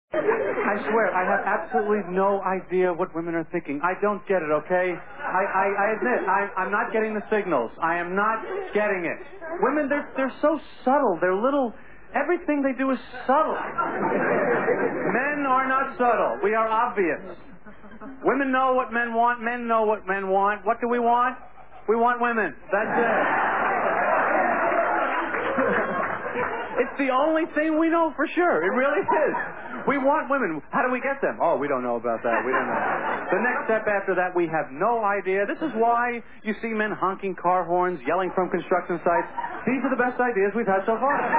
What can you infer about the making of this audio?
Stand up performing): Men want Women!!! These are the best ideas we’ve had so far.wav